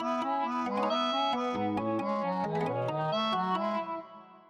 MIDI Composition
They are short and experimental.